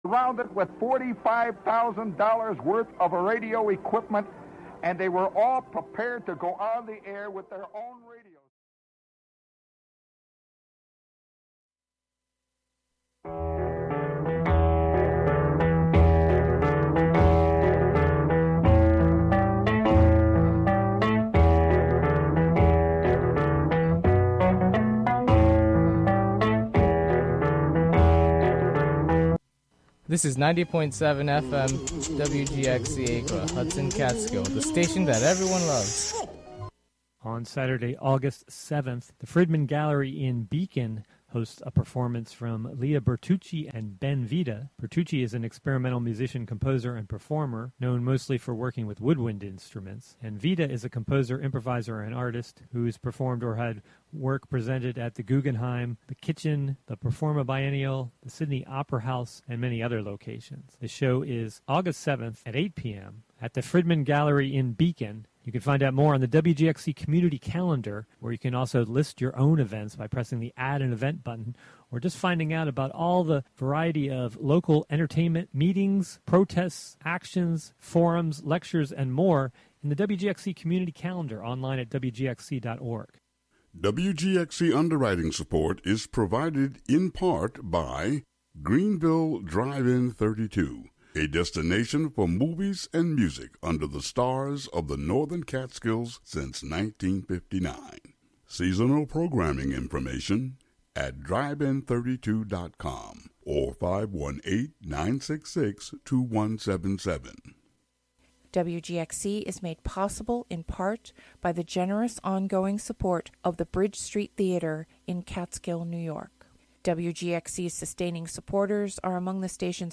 Canadian singer-songwriter
blended folk, pop, and jazz